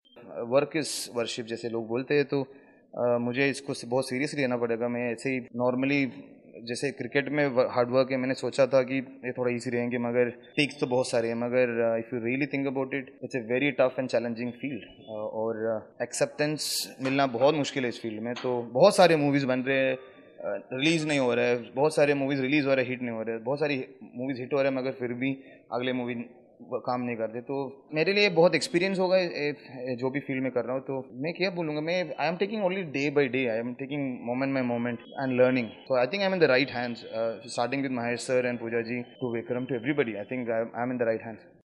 सुनिए बॉलीवुड में आगाज़ करने जा रहे क्रिकेटर एस.श्रीसंत का क्या कहना है अपनी नई पारी पर.